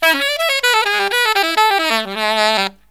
63SAXMD 02-L.wav